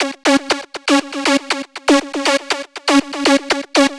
TSNRG2 Lead 020.wav